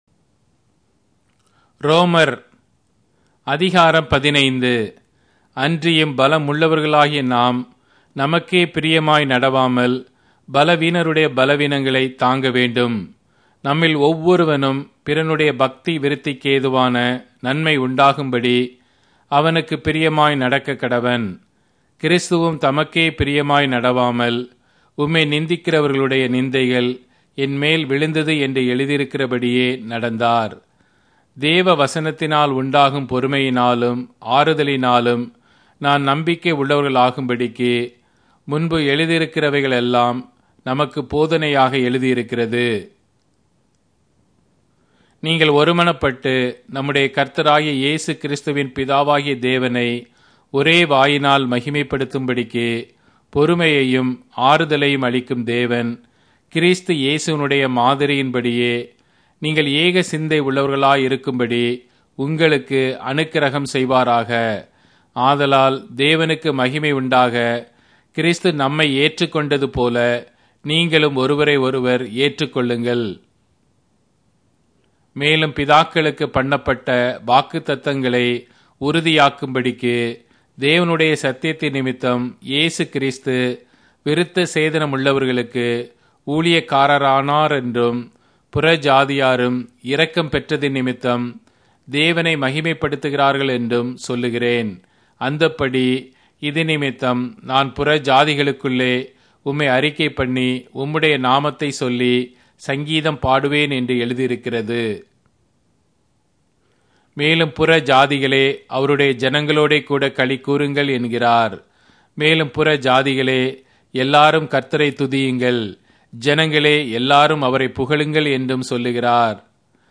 Tamil Audio Bible - Romans 12 in Ncv bible version